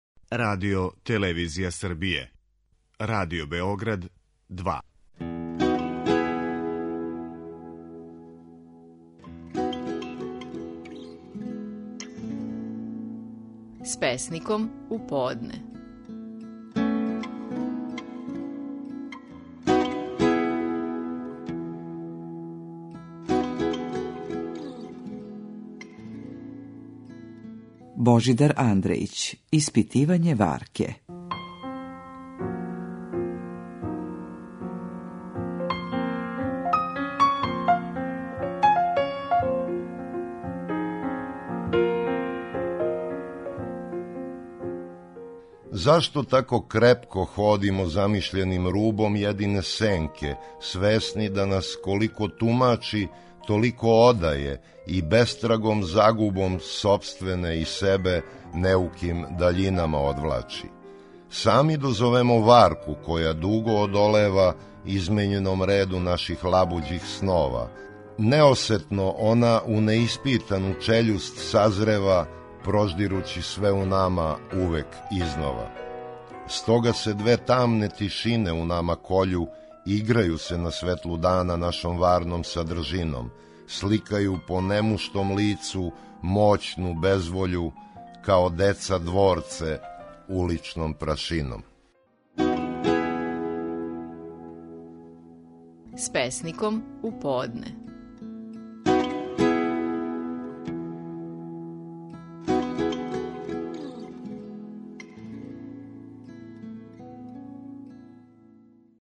Наши најпознатији песници говоре своје стихове